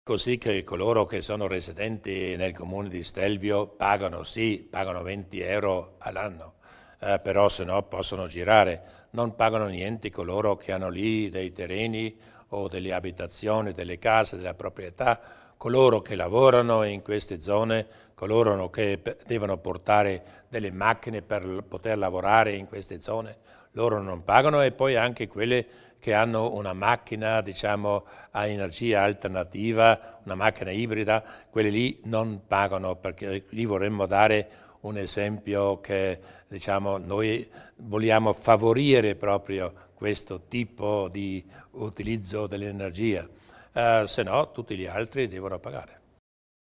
Il Presidente Luis Durnwalder spiega le novità in tema di passo Stelvio